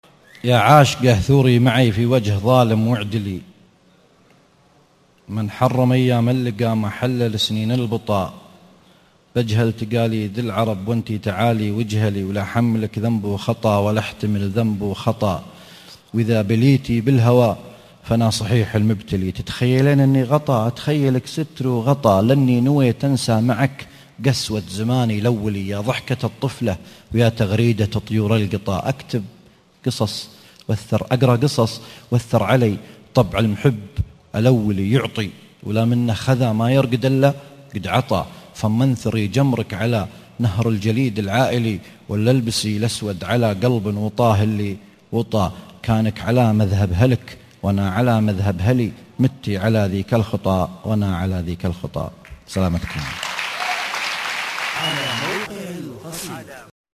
ياعاشقة ( امسية سان دييغو 2013 )